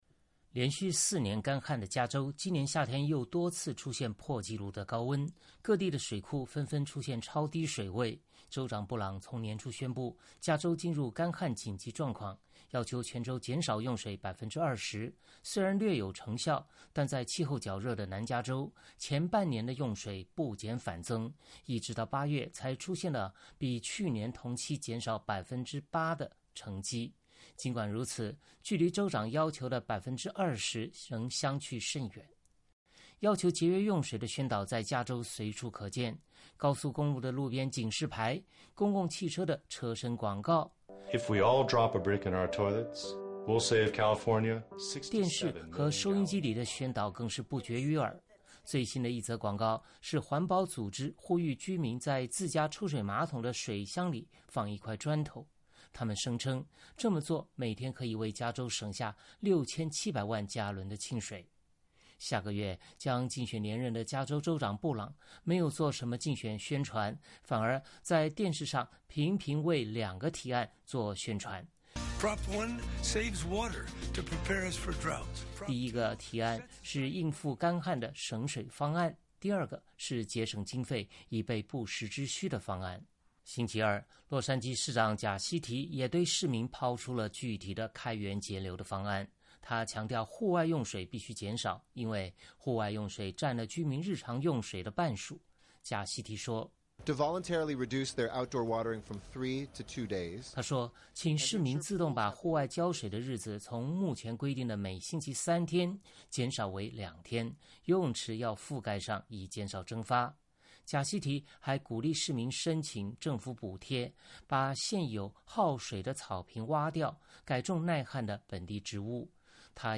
洛杉矶 —